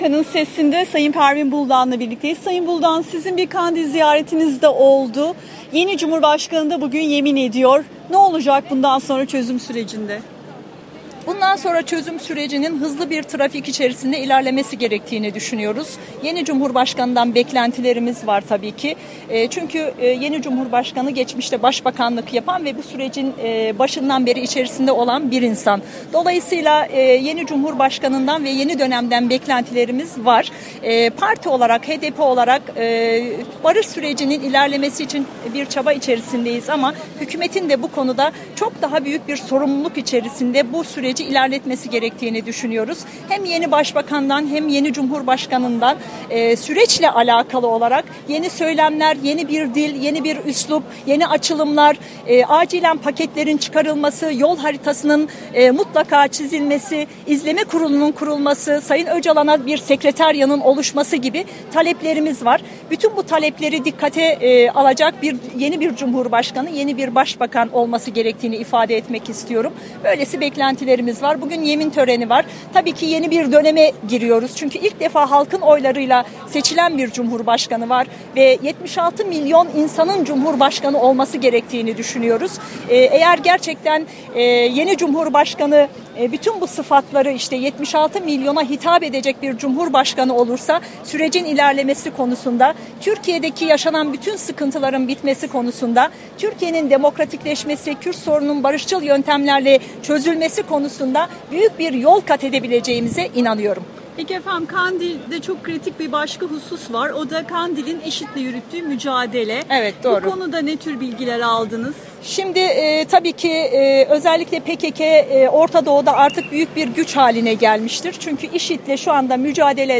Pervin Buldan ile Söyleşi